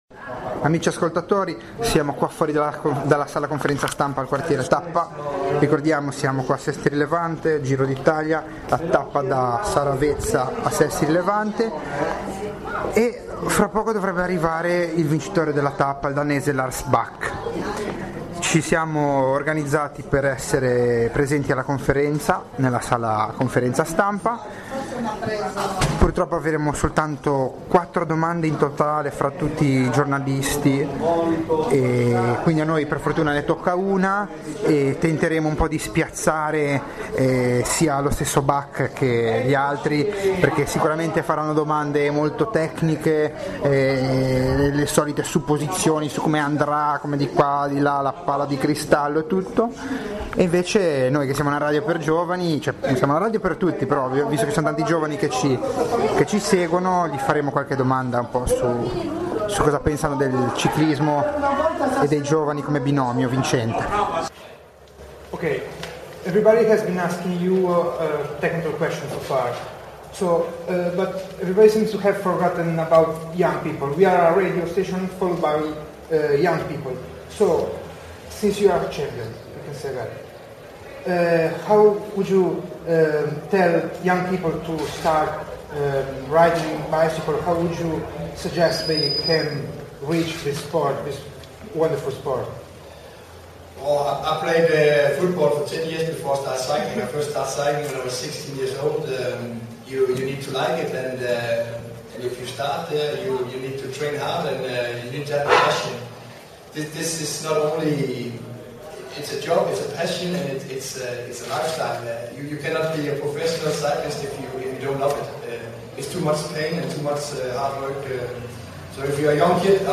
Intervista a Giro d'Italia (Tappa Saravezza - Sestri Levante)
play_circle_filled Intervista a Giro d'Italia (Tappa Saravezza - Sestri Levante) Radioweb C.A.G. di Sestri Levante Ciclisti professionisti e pubblico intervista del 17/05/2012 Intervista al giro D'Italia e precisamente alla Tappa Saravezza - Sestri Levante. Tra gli intervistati oltre ai fans, la maglia rosa Joachim "Purito" Rodriguez, il vincitore di tappa Lars Bak (danese) e il ciclista basco Mikel Nieve.